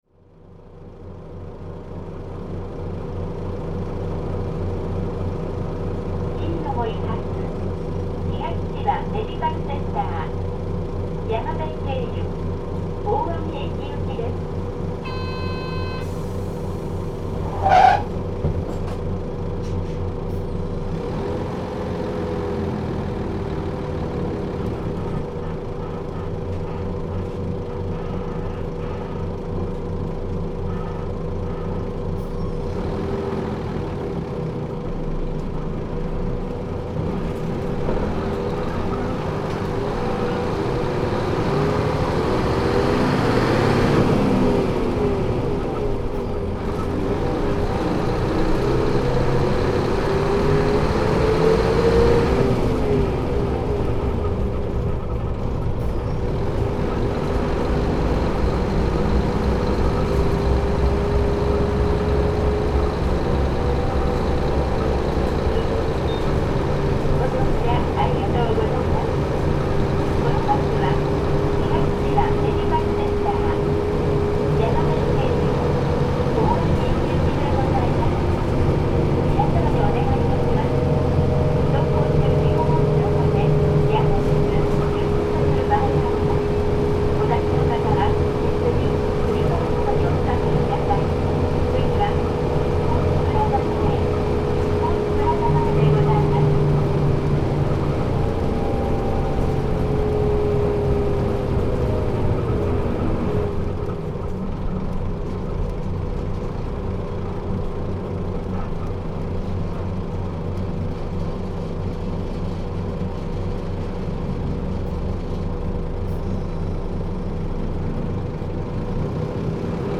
小湊鐡道 いすゞ KC-LR333J ・ 走行音(全区間) (28.2MB*) 収録区間：季美の森線 大網17系統 大網駅→季美の森 いすゞジャーニーシャーシの富士8E。